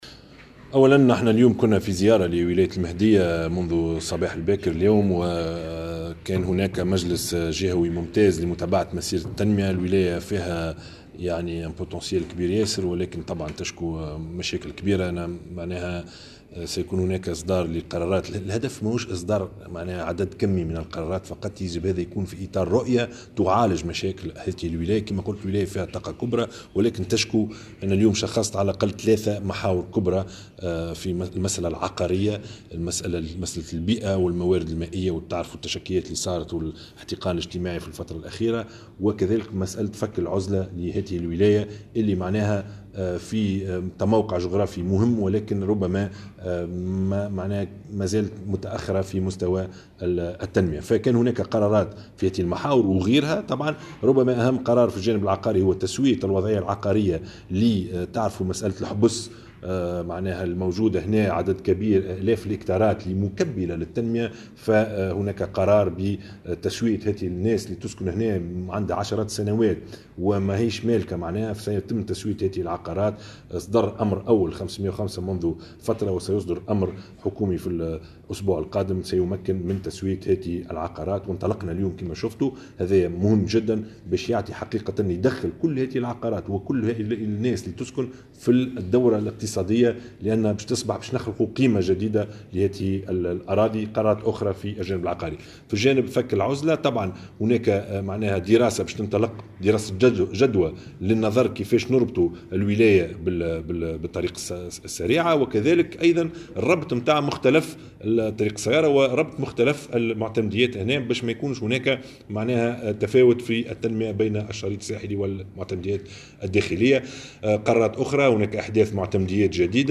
أكد رئيس الحكومة في تصريح لمراسل "الجوهرة أف أم" مجموعة القرارات التي تم اتخاذها اليوم السبت بمناسبة انعقاد جلسة ممتازة للمجلس الجهوي بولاية المهدية، بينها احداث معتمديات جديدة في رجيش والبرادعة. كما شملت هذه القرارات المسائل العقارية والبيئية مشددا على اهمية فك العزلة التي تعاني منها ولاية المهدية رغم تموقعها الجغرافي المهم، حسب تعبيره.